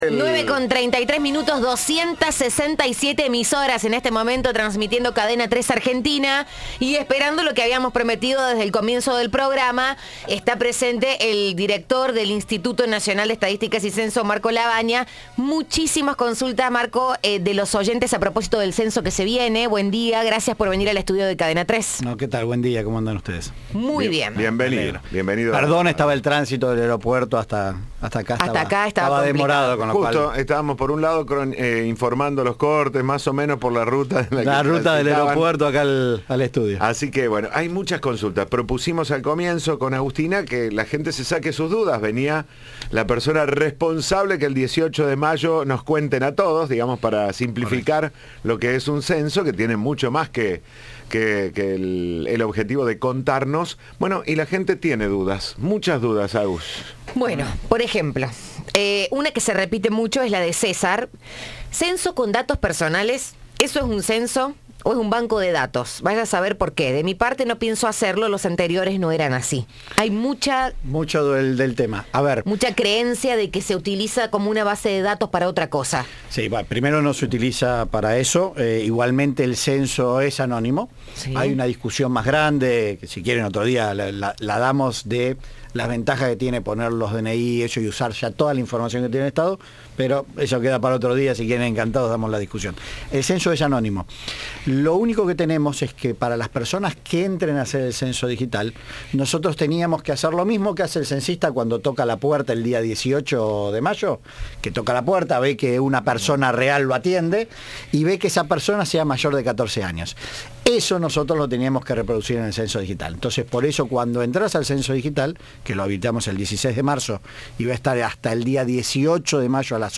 El director del Indec, Marco Lavagna, estuvo en Cadena 3 y respondió las preguntas de los oyentes sobre el cuestionario, qué actividades pueden desarrollarse ese día y cuestiones orientadas a la seguridad.